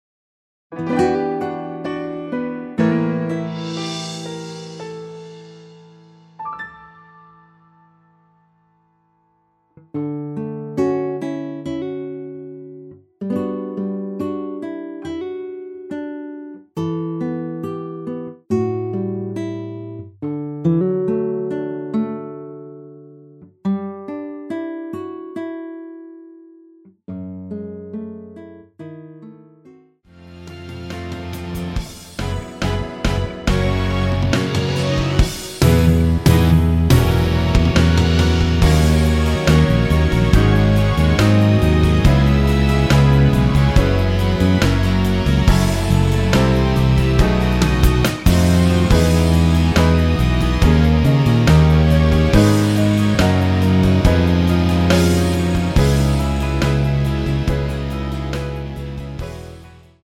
원키에서(+2)올린 MR입니다.
앞부분30초, 뒷부분30초씩 편집해서 올려 드리고 있습니다.